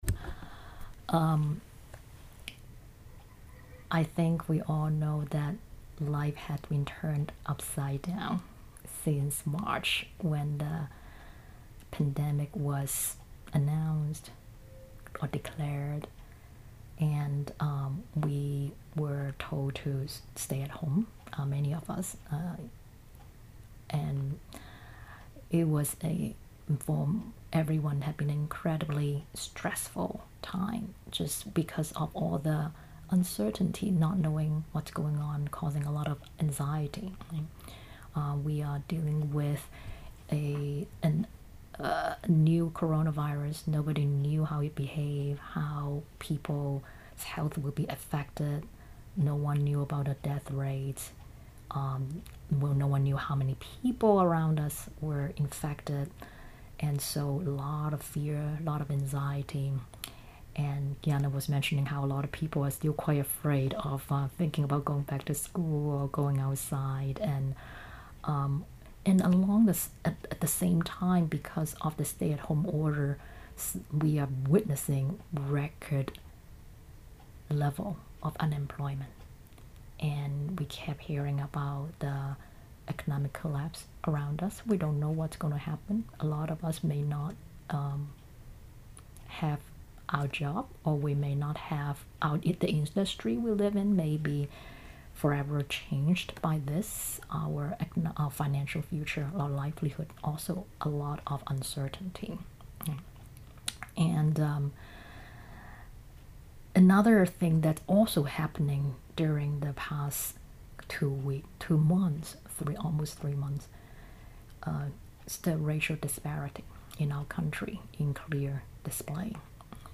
This interview is part of the Mindfulness and Meditation Summit, a free online event where you can return to joy, foster courage and share compassion through the power of mindful awareness.